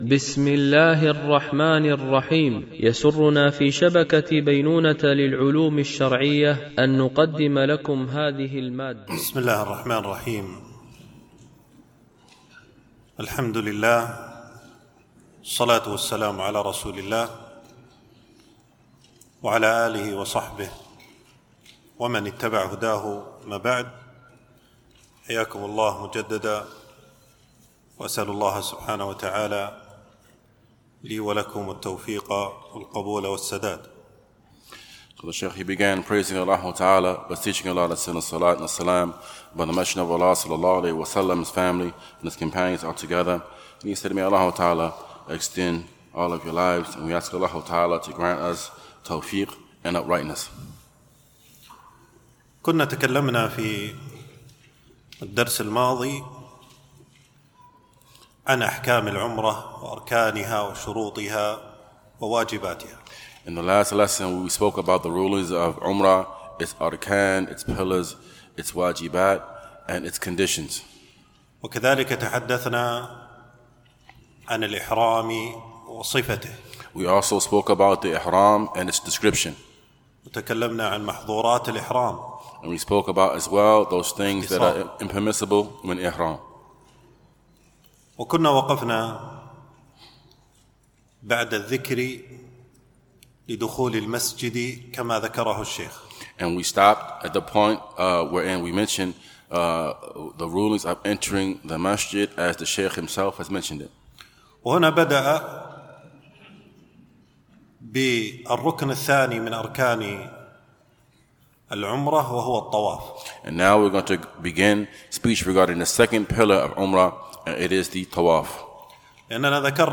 دورة علمية مترجمة للغة الإنجليزية، لمجموعة من المشايخ، بمسجد أم المؤمنين عائشة رضي الله عنها